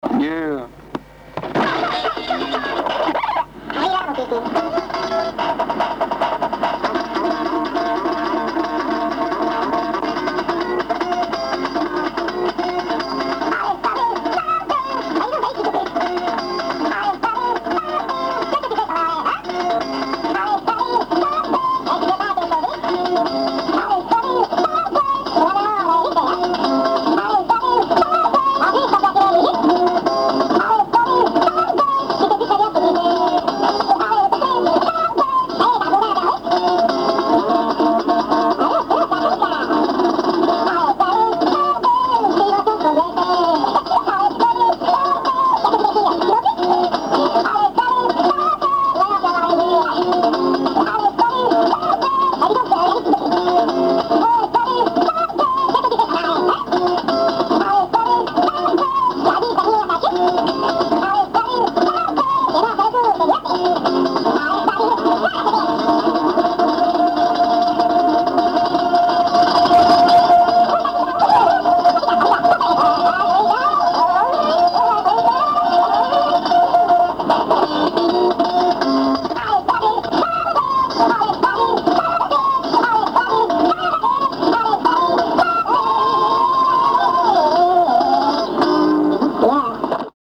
disque entiérement composé et mal  enregistré devant la télé